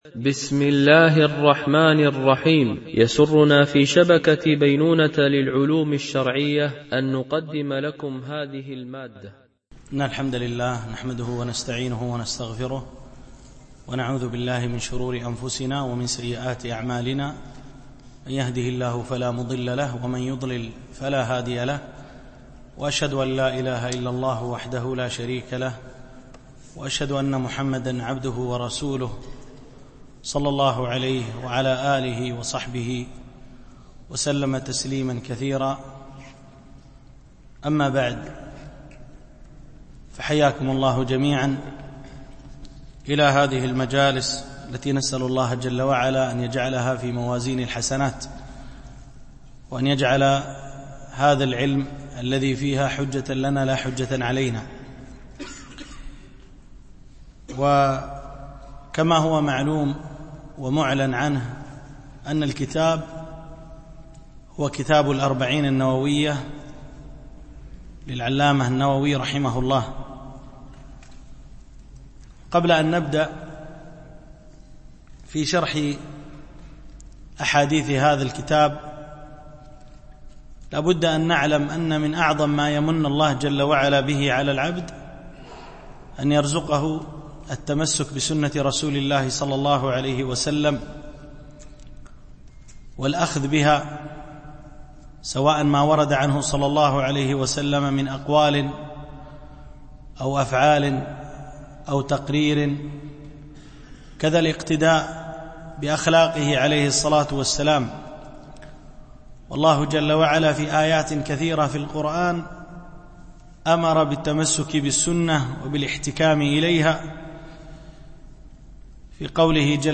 شرح الأربعين النووية - الدرس 1 (المقدمة، والحديث 1)